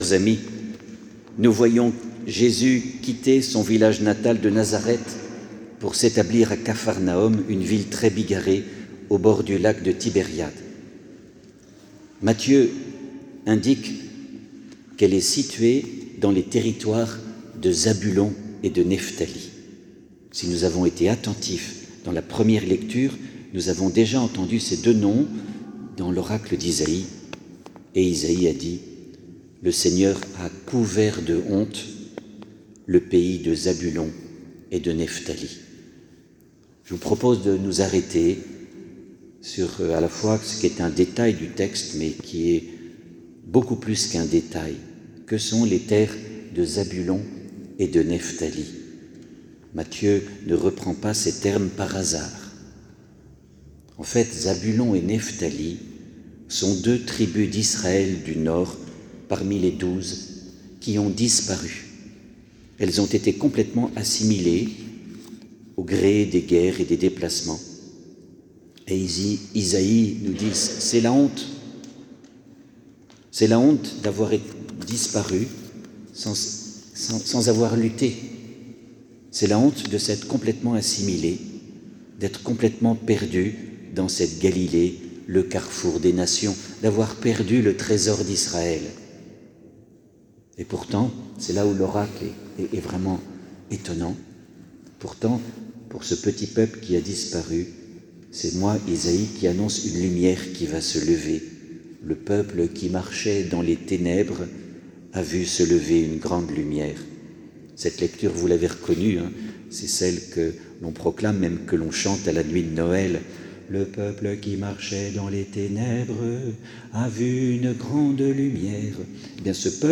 Homélie du 25/01/26 : Zabulon et Nephtali
Enregistrement audio de l’homélie